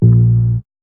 0508R BASS.wav